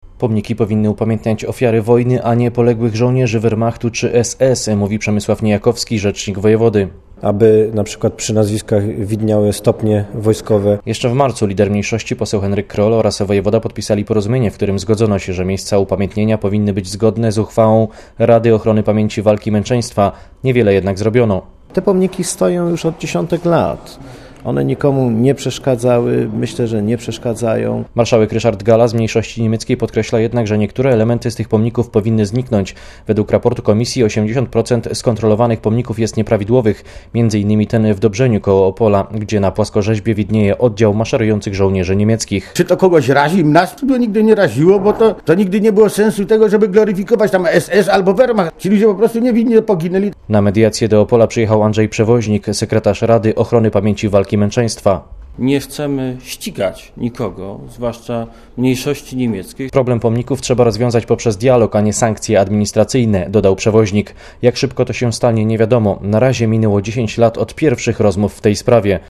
Z Opola mówi reporter Radia Zet